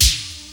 CD SLAP SD.wav